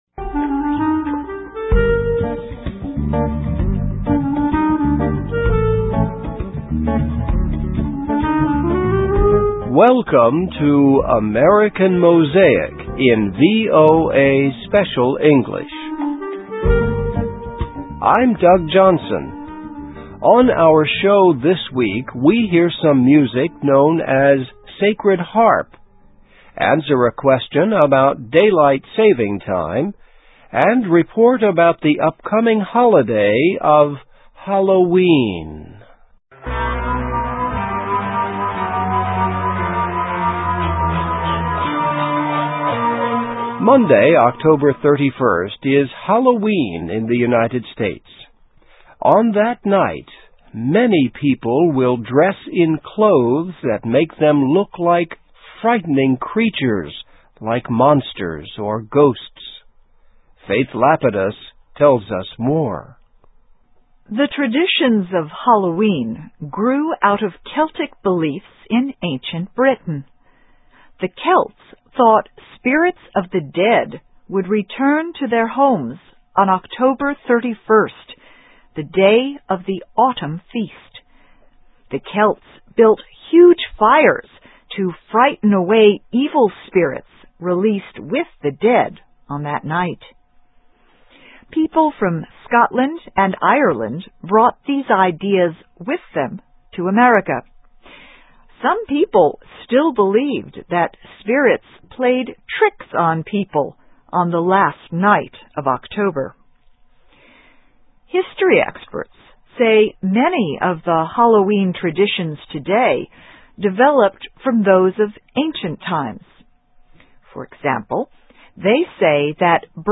Listen and Read Along - Text with Audio - For ESL Students - For Learning English